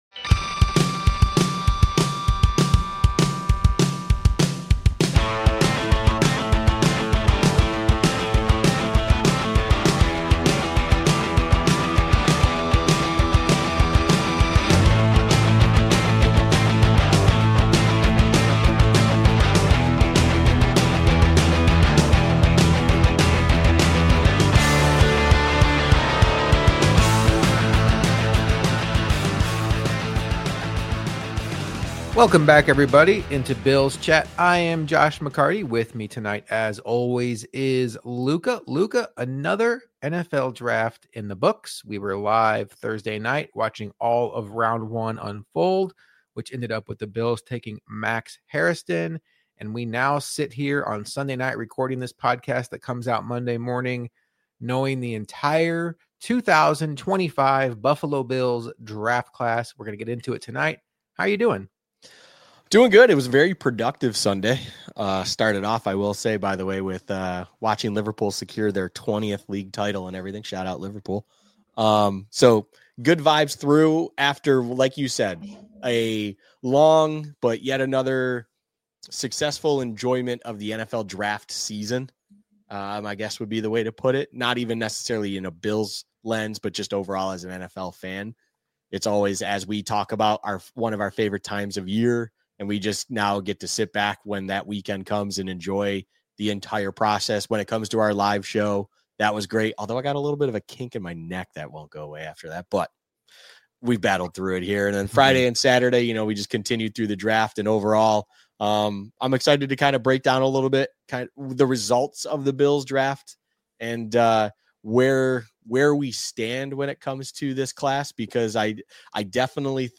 Bills Cook Dolphins on TNF (Live from Friday 9/13) – Bills Chat: A Pro Football Podcast – Podcast